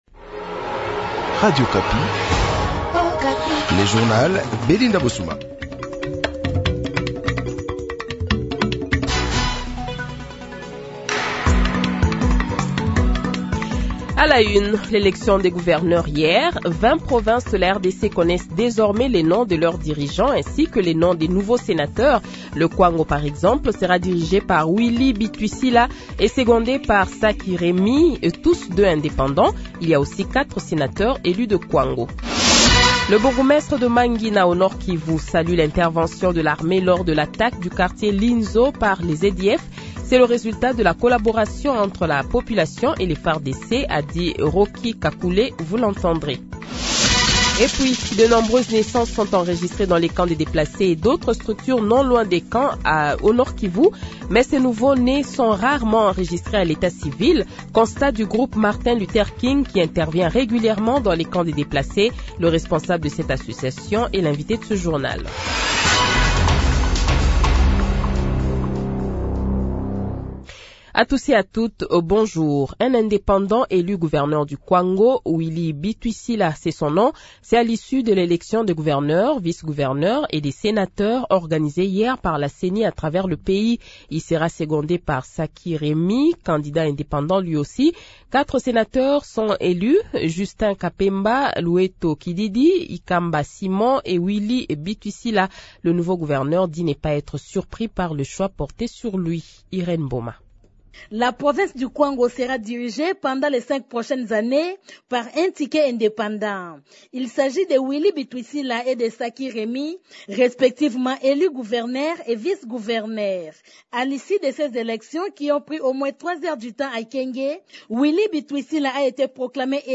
Le Journal de 8h, 30 Avril 2024 :